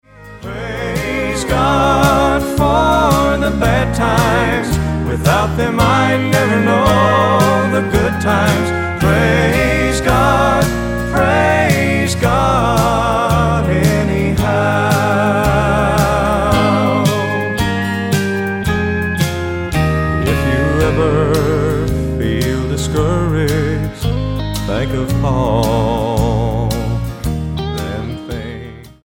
STYLE: Southern Gospel
tender harmonies